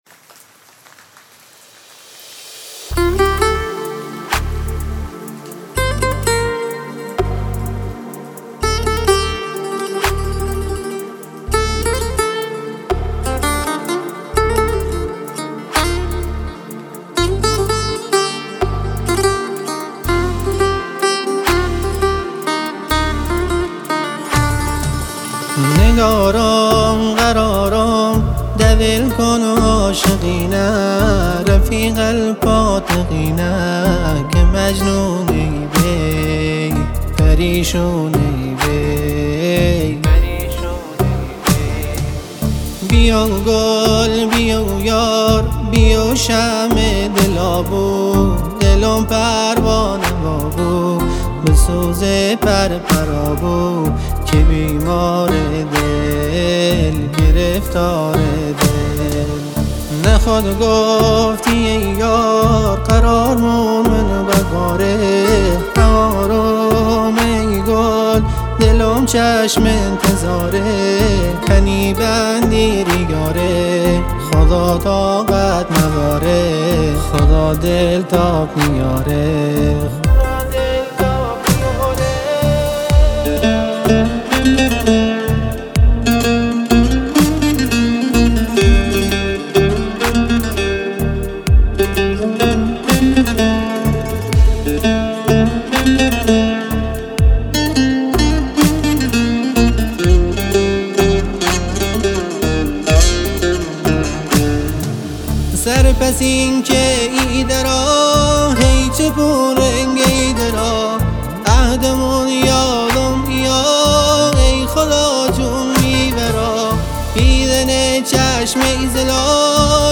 دانلود آهنگ لری